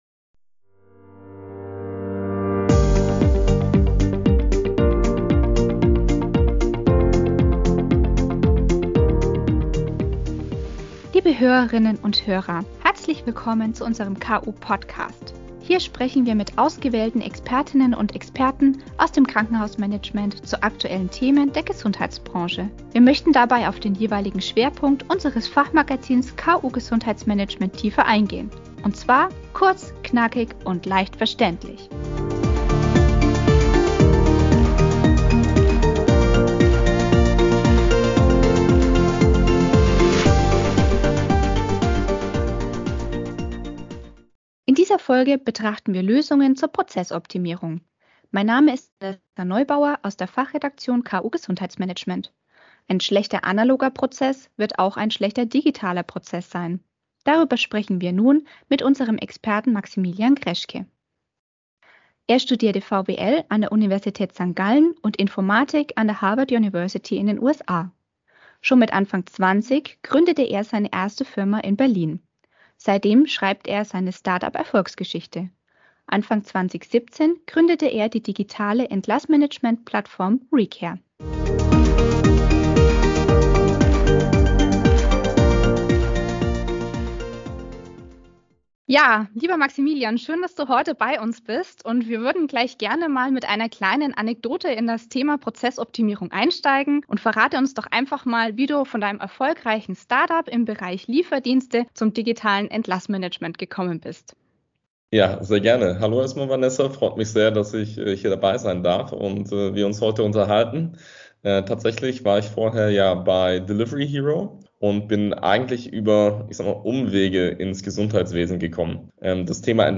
Interviewpartner